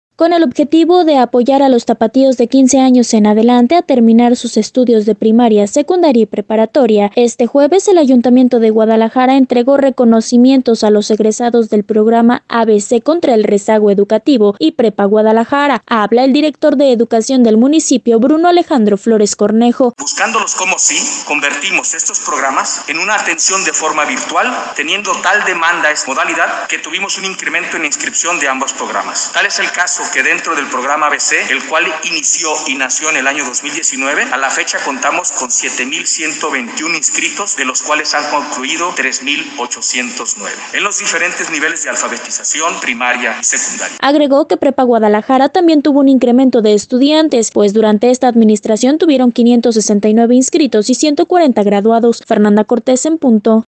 Habla el Director de Educación del municipio, Bruno Alejandro Flores Cornejo: